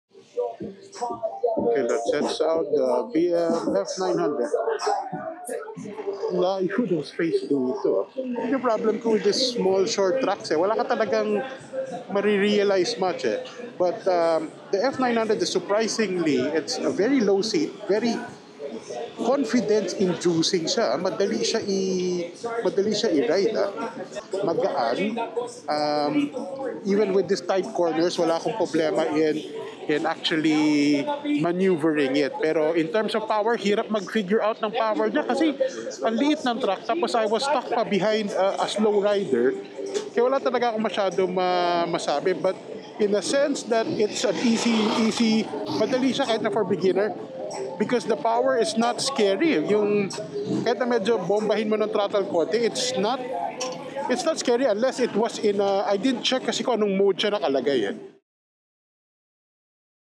Test Ride BMW F900 sound effects free download
Test Ride - BMW F900 GS during the Manila Moto Off Road 2025